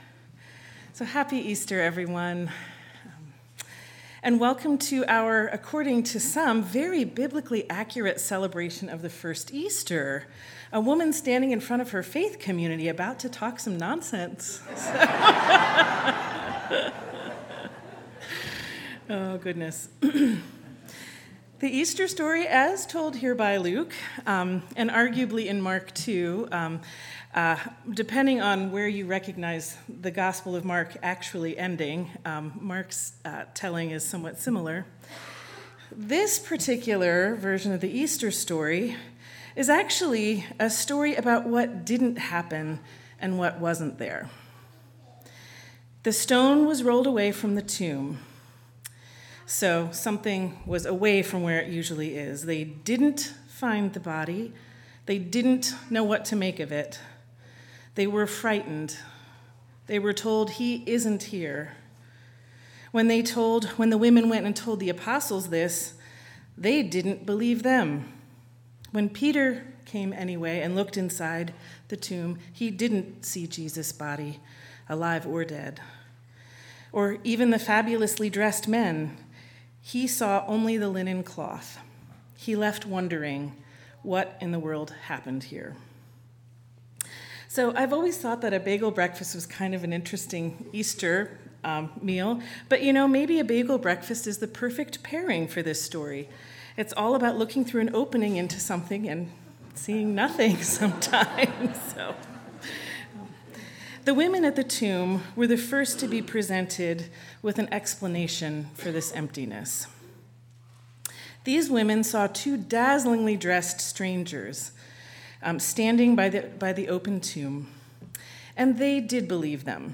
3/31/24 Sermon